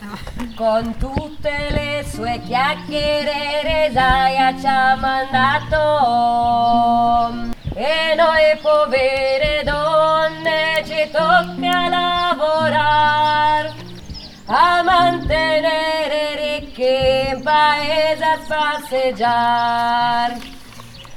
E_anche_per_quest_anno_ALTI.mp3